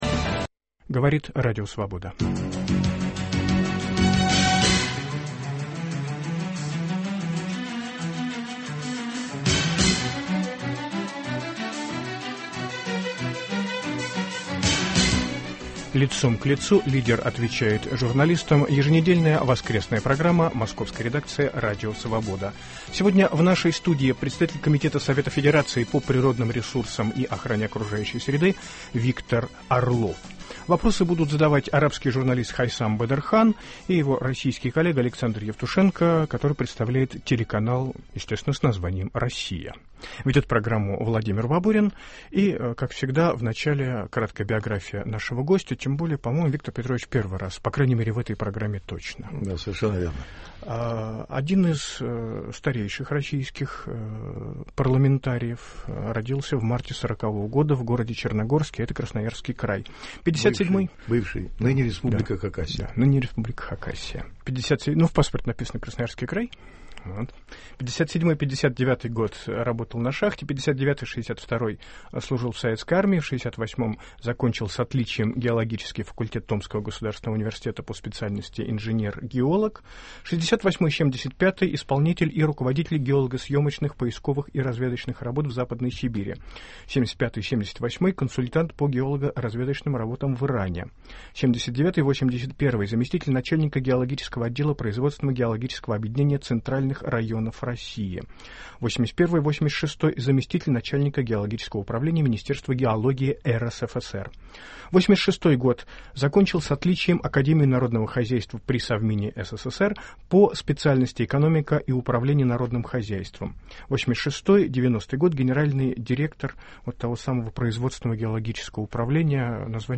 Гость программы - председатель Комитета Совета Федерации по природным ресурсам и охране окружающей среды, представитель от исполнительного органа государственной власти Камчатского края Виктор Орлов.